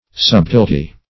subtilty - definition of subtilty - synonyms, pronunciation, spelling from Free Dictionary
Subtilty \Sub"til*ty\, n. [Contr. fr. subtility.]